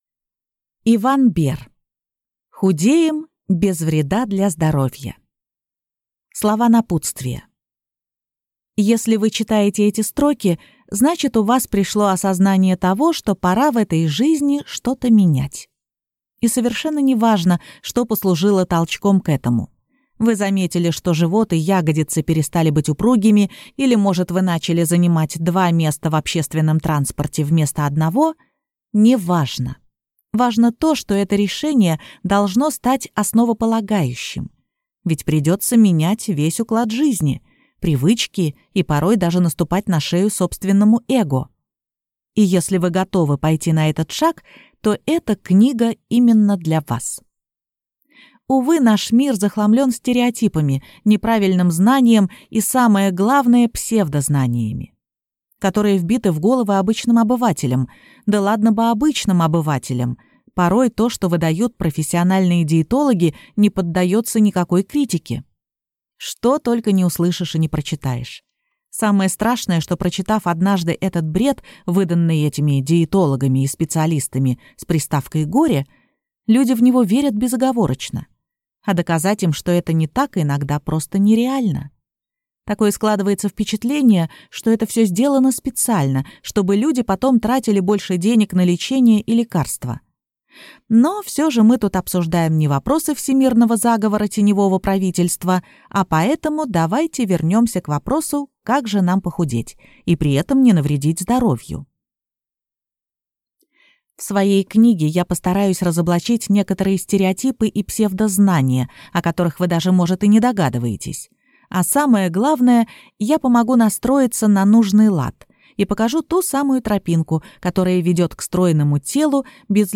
Аудиокнига Худеем без вреда для здоровья | Библиотека аудиокниг
Прослушать и бесплатно скачать фрагмент аудиокниги